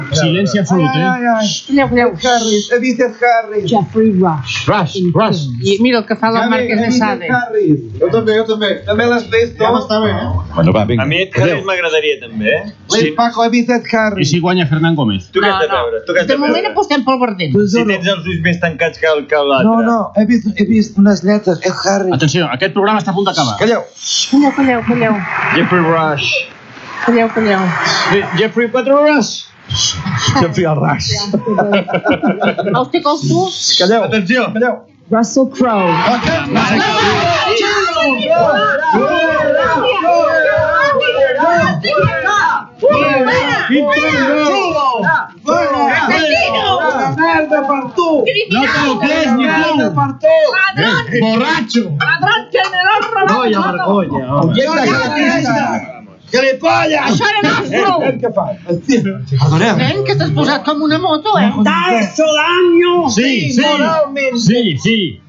Programa especial d'El Terrat, durant la cerimònia de lliurament dels Oscar, els premis de l'Acadèmia de Cinema. Moment que s'anuncia el guanyador com a millor actor. Reacció dels presentadors i personatges quan es diu el nom de Russell Crowe.
Entreteniment